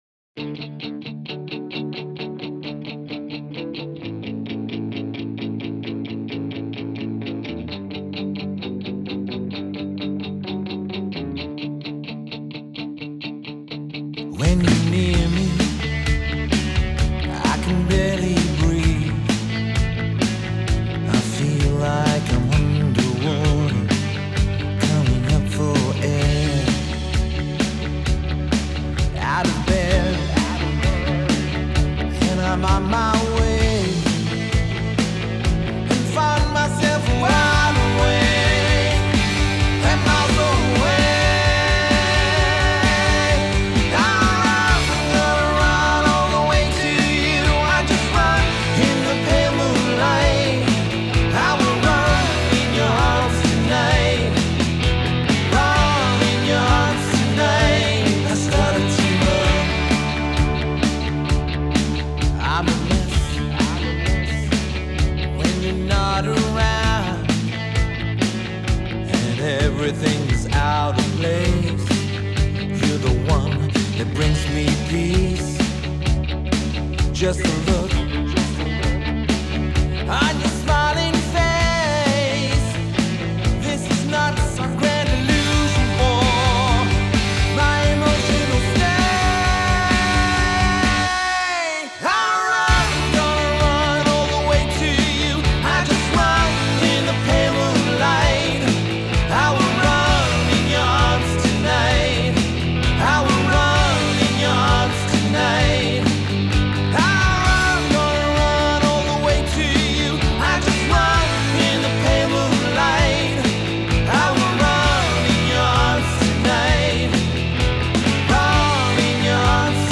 Just a very rough mix of what I got so far.
plays drums and bass and the crappy sounding guitars
synth keys
Additional great sounding guitars and guitar solo